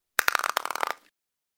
Звуки скелета
Звук трескающегося скелета